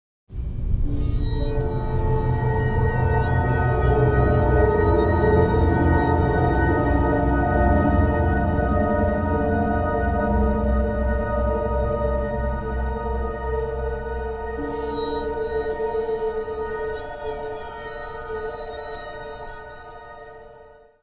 processed guitar, sampler